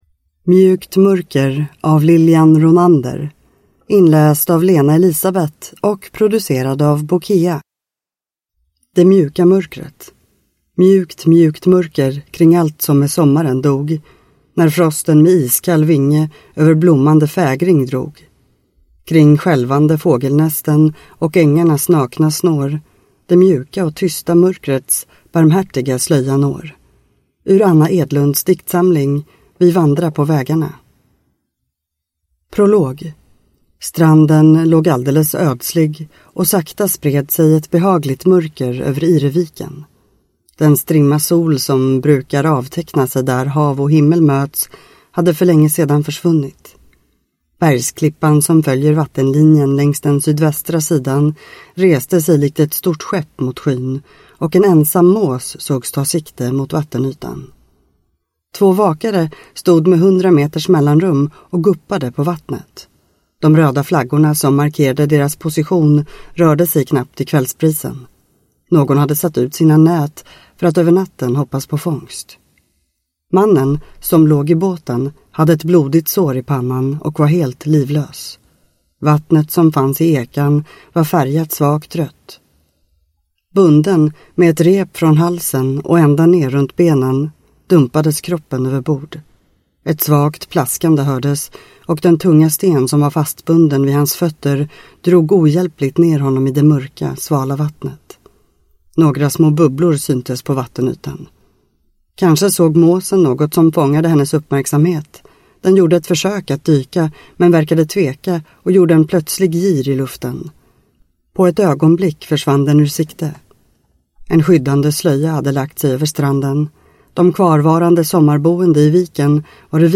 Mjukt mörker (ljudbok) av Lilian Ronander